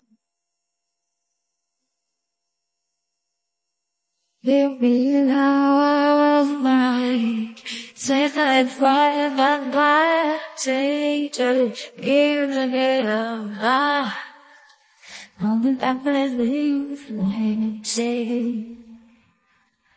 Lyrics-free Singing Voice Generation Taiwan AILabs
In contrast to this conventional approach, we are interested in a singing voice generation model that does not take any lyrics and MIDI scores as input, but instead decides the phonemes and pitches underlying its singing pretty much on its own.
generated.12-21.mp3